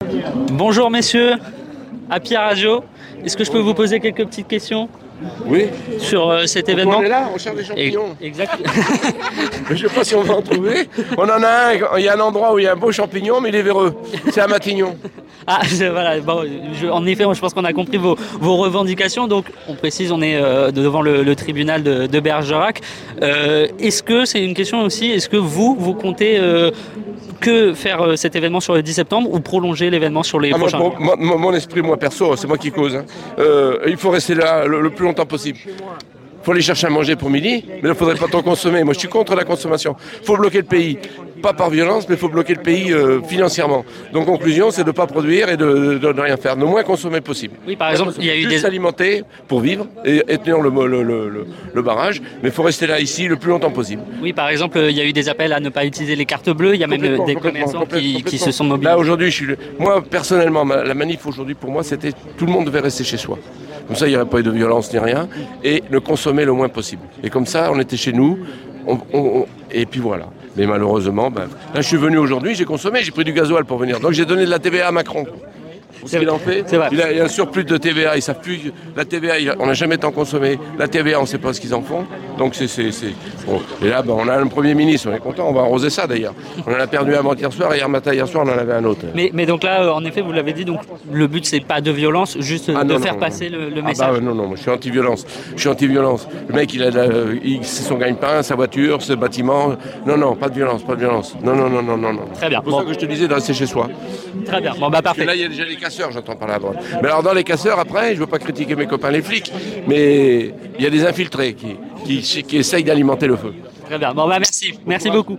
LES INTERVIEWS HAPPY RADIO – MANIFESTATION BERGERAC – MILITANT CGT